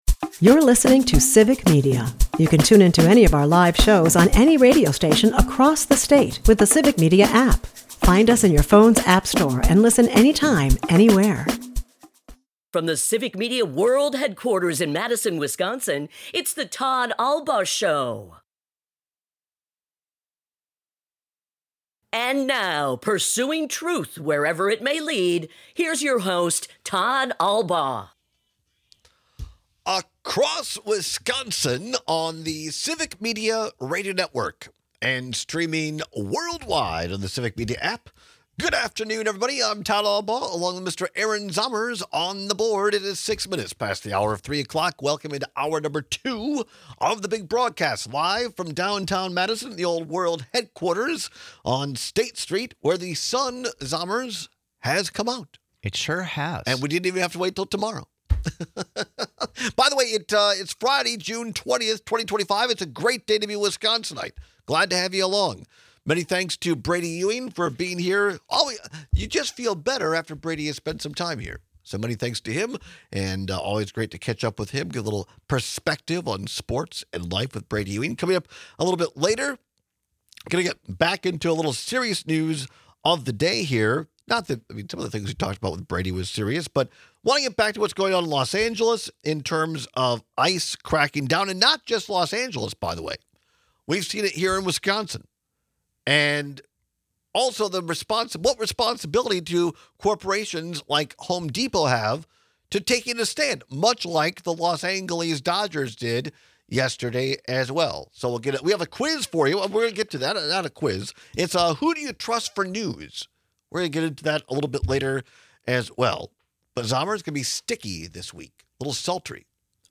We take some calls and texts about whether you’d rather own an old car or borrow a new one.&nbsp